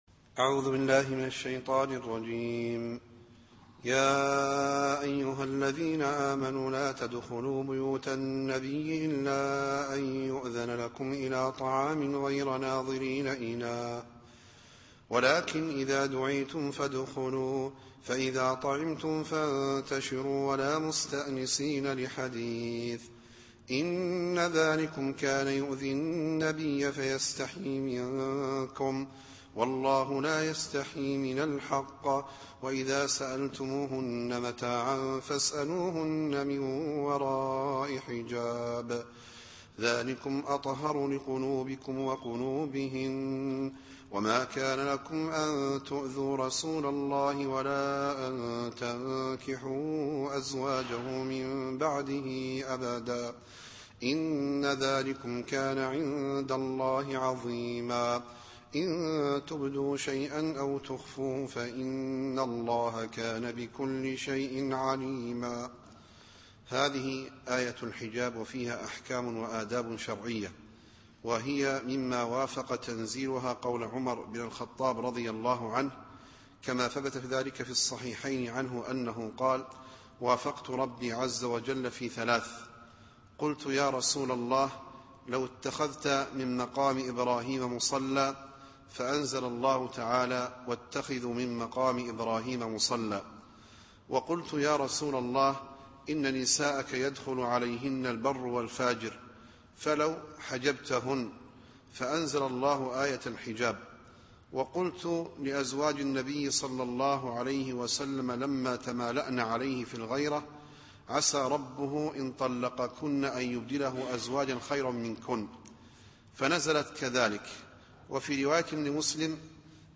التفسير الصوتي [الأحزاب / 53]